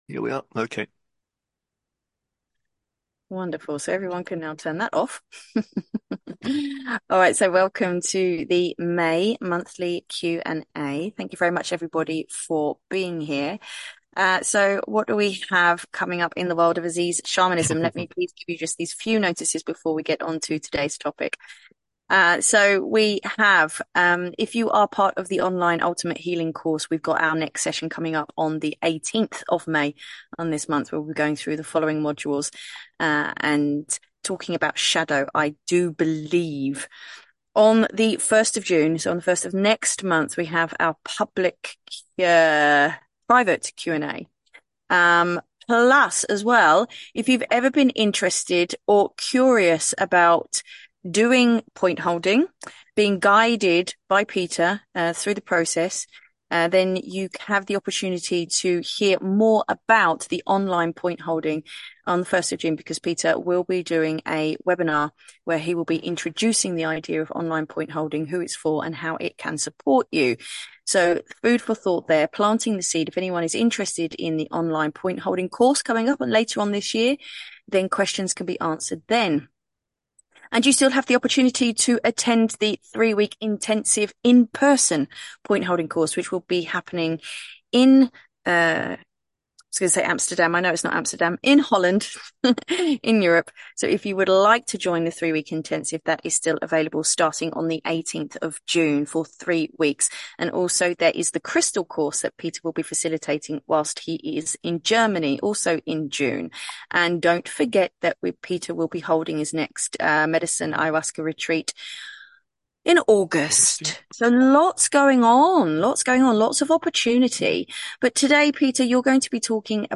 Call Replays